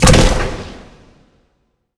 sci-fi_weapon_rifle_boomer_01.wav